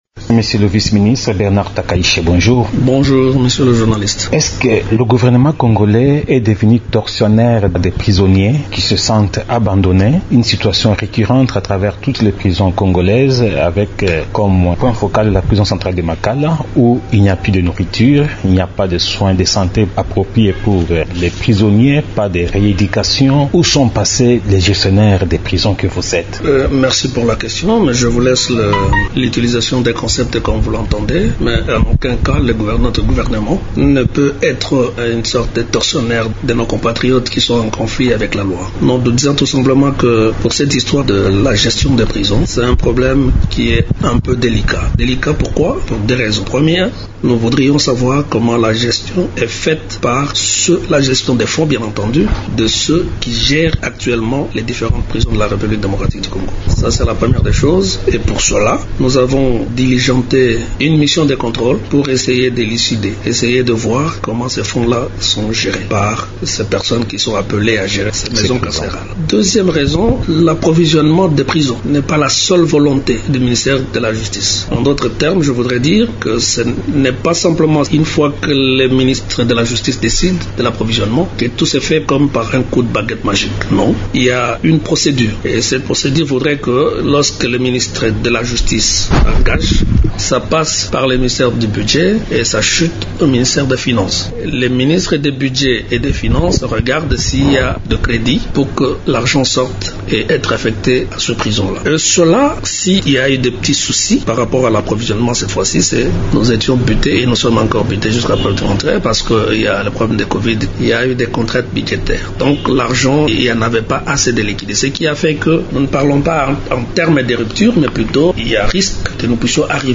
Le gouvernement a diligenté une mission de contrôle pour essayer d’élucider la manière dont les fonds alloués aux prisons sont gérés. Déclaration du vice-ministre de la justice, Bernard Takayishe dans une interview exclusive à Radio Okapi.
bernard_takayishe_.mp3